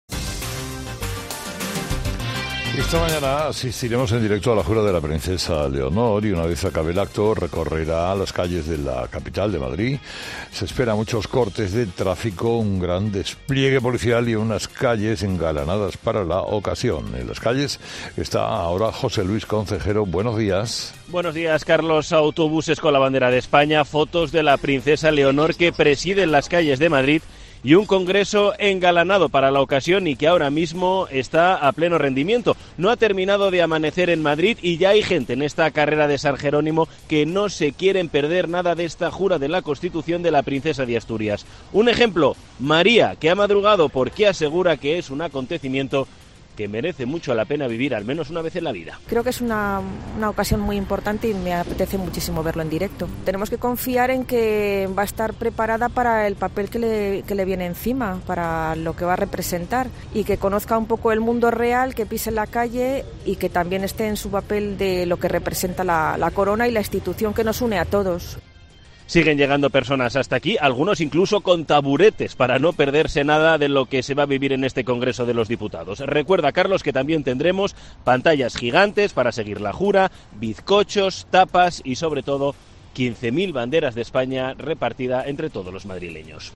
La princesa Leonor jura la Constitución en el Congreso 14.00 | Así han repicado las campanas de la Catedral de Oviedo por la jura de la Constitución de Leonor.